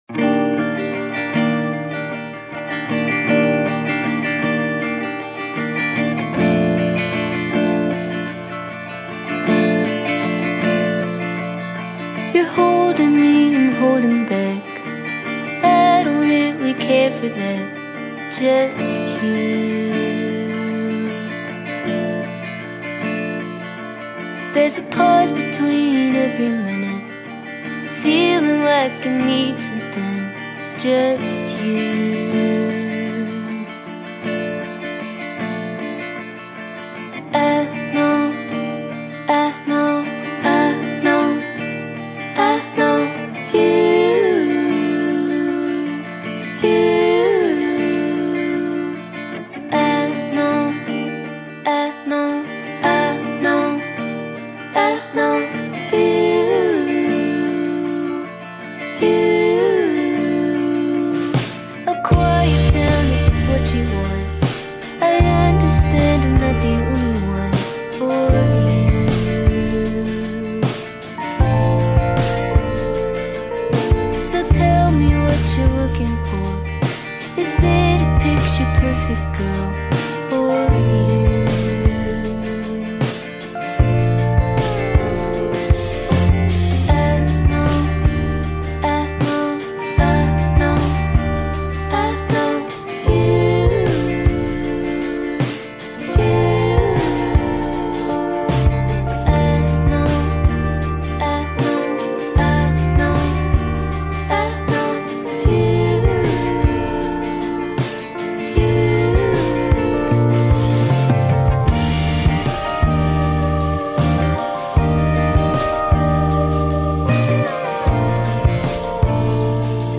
سبک هیپ هاپ